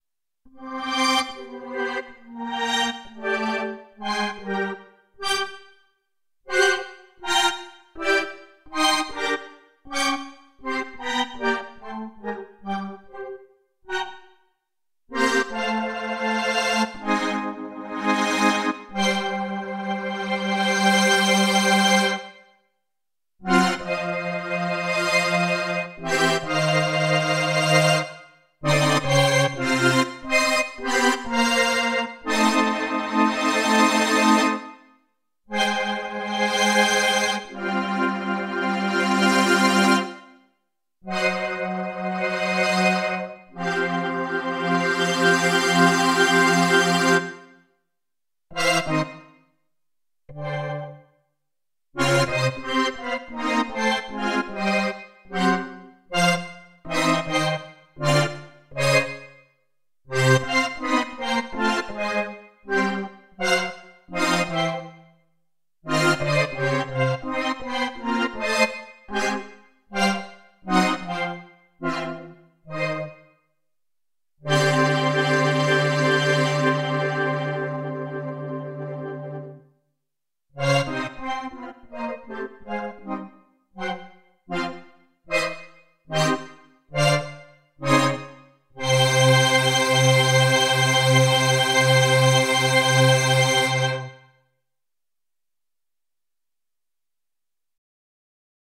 Some chord rotation noodling from the weekend with V2.
Shimmery organ one, this chord rotation repeats once every 78 notes: